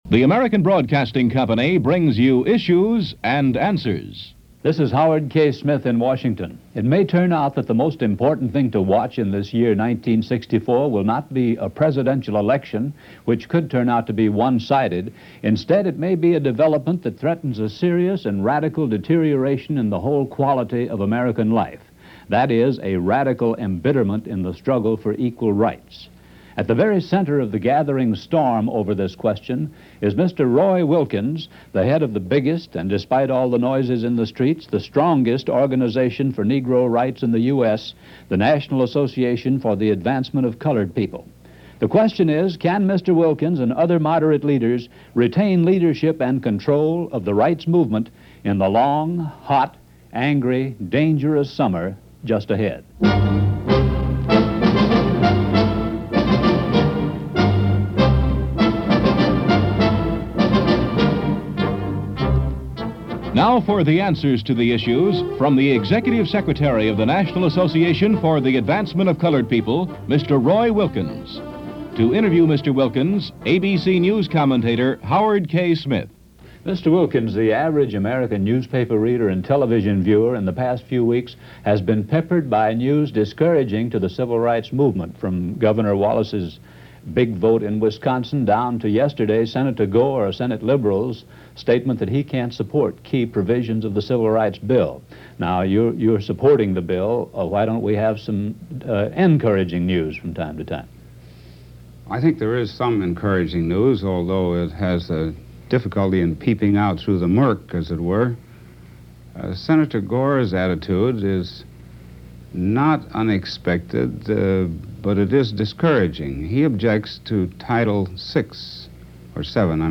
ABC Radio – Issues and Answers – Roy Wilkins – Interviewed by Howard K. Smith